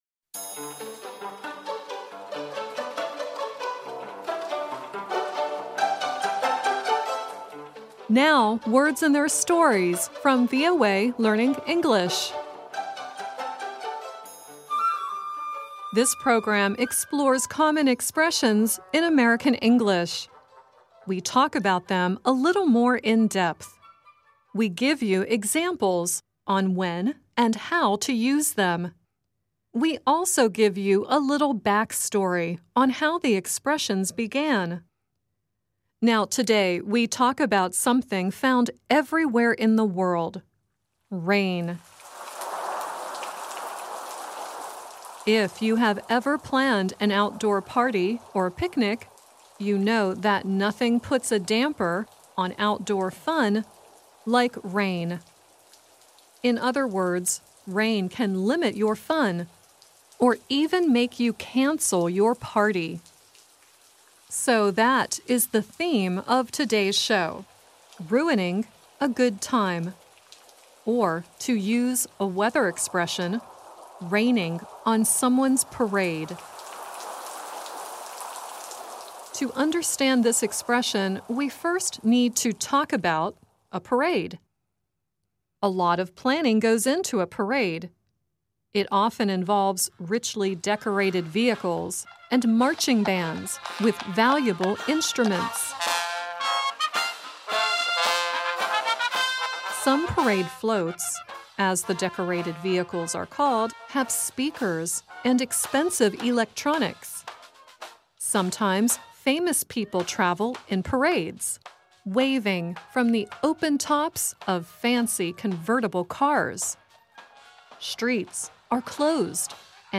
The song at the end is Idina Menzel singing "Don't Rain on My Parade," courtesy of The Kennedy Center.